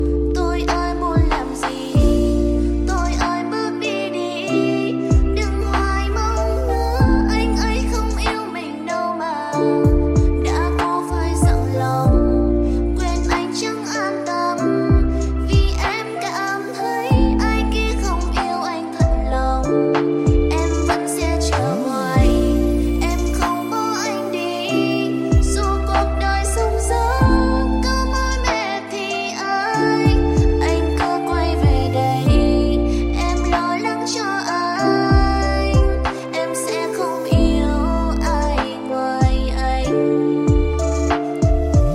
Nhạc Trẻ